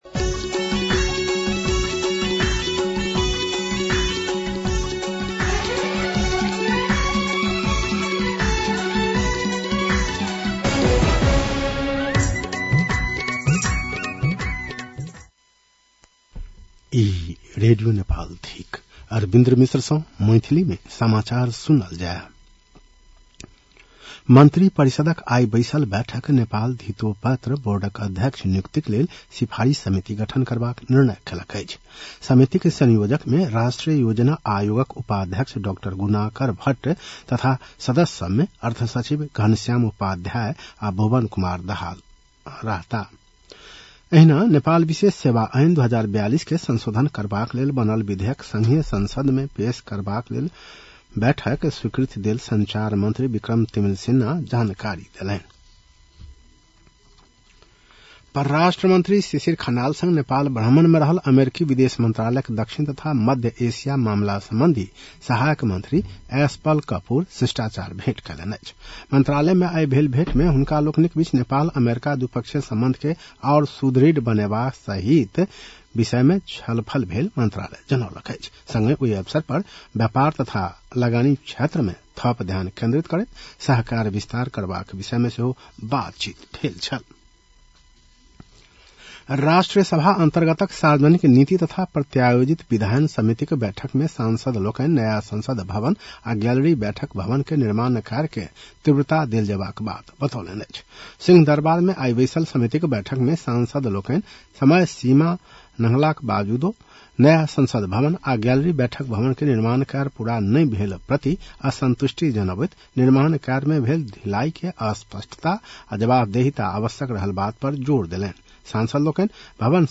An online outlet of Nepal's national radio broadcaster
मैथिली भाषामा समाचार : ८ वैशाख , २०८३